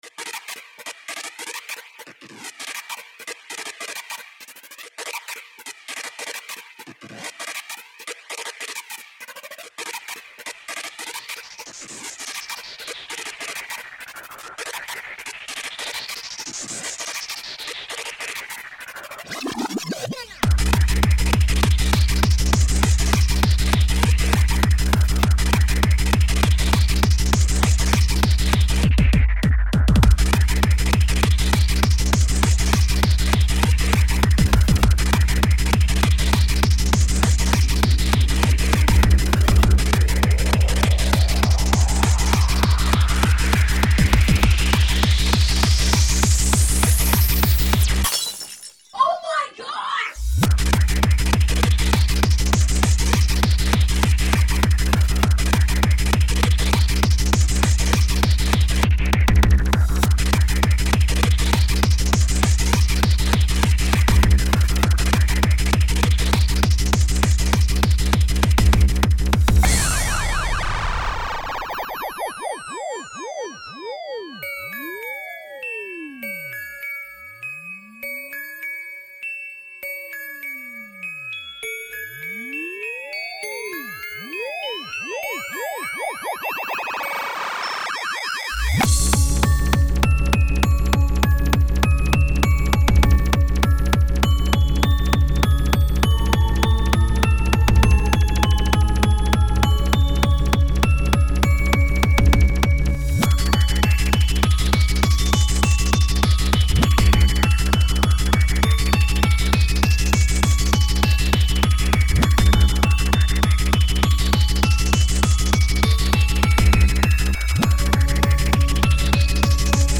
09/10/2013 Etiquetes: Tribecore Descàrregues i reproduccions